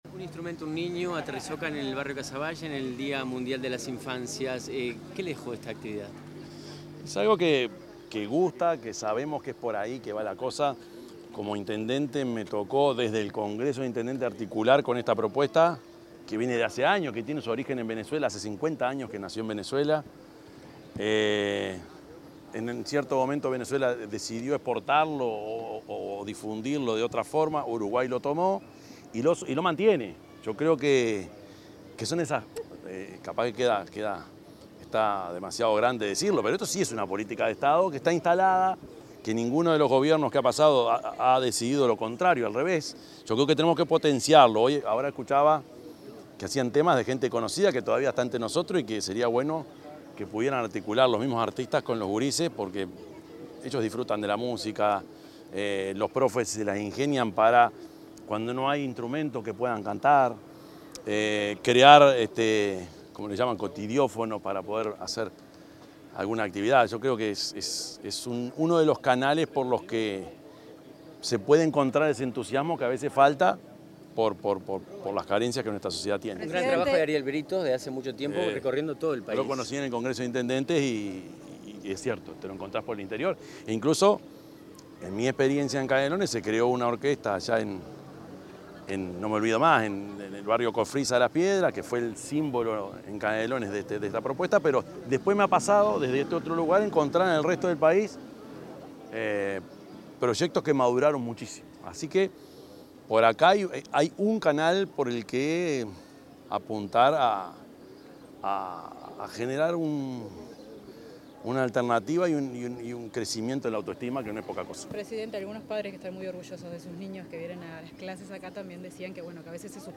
Declaraciones del presidente de la República, Yamandú Orsi
Declaraciones del presidente de la República, Yamandú Orsi 20/11/2025 Compartir Facebook X Copiar enlace WhatsApp LinkedIn Al finalizar la actividad denominada “Casavalle en concierto”, el presidente de la República, Yamandú Orsi, brindó declaraciones a la prensa sobre la iniciativa y el impacto que tiene el aprendizaje musical en los niños y en las comunidades.